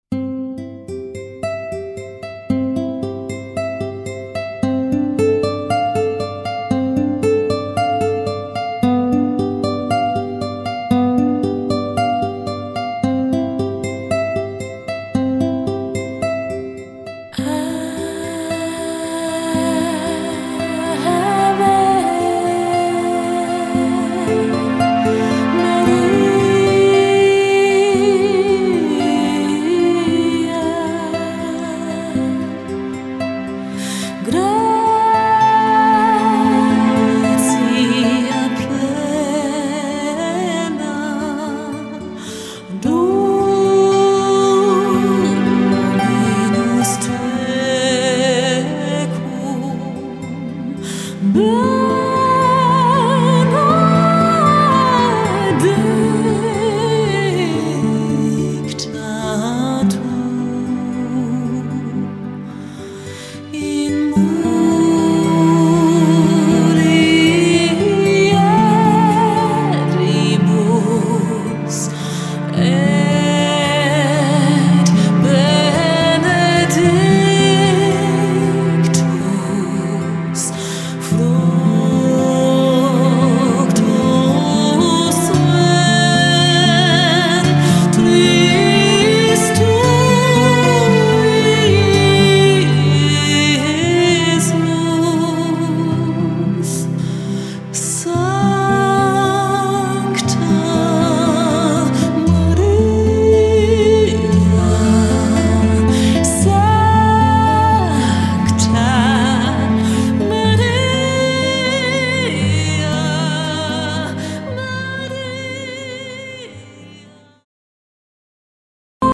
das Blöde ist, wir haben auf der großen Hochzeitsmesse in München ein echtes Ausnahme-Duo gehört, das eigentlich normalerweise konzertant unterwegs ist, in das wir uns sofort stehend verliebt haben.